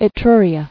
[E·tru·ri·a]